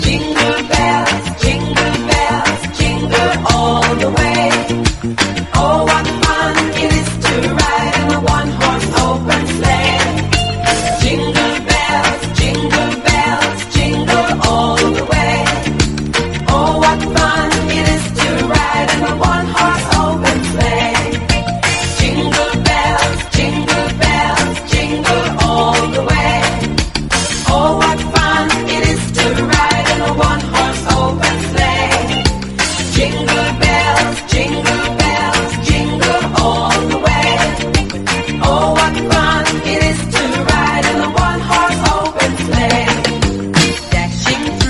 WORLD / REGGAE / SKA/ROCKSTEADY / KIDS / CHILDREN / REGGAE
スティールパンも入るハッピー度マックスなアレンジ＆子供声もあどけないキュートでトロピカルなカヴァーが最高！